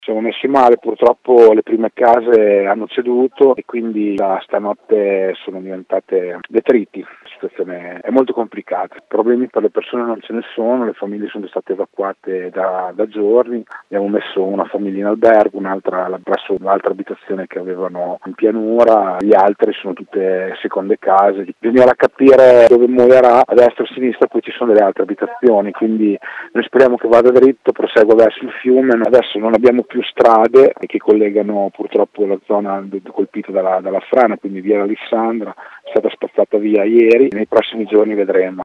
Il sindaco di Palagano Braglia ha chiesto lo stato di emergenza regionale e nazionale: